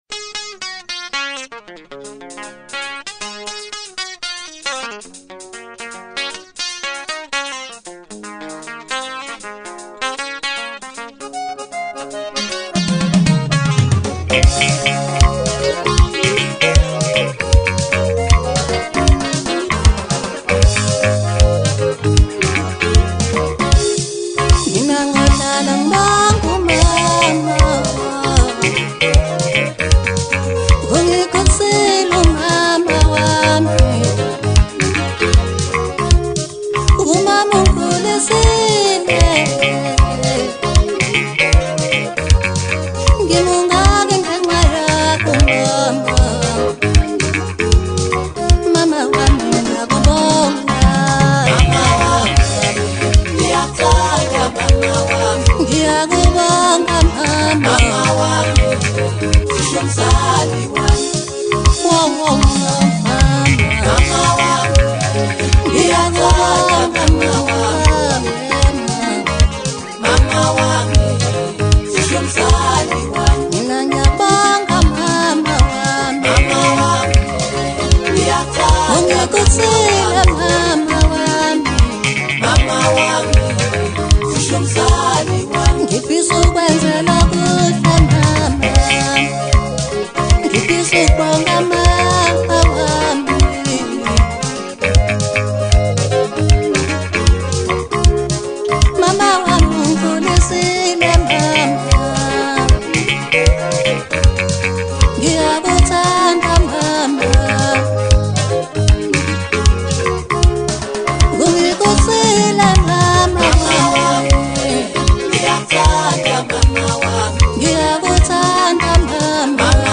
Home » Hip Hop » Maskandi
smooth sound, balanced mood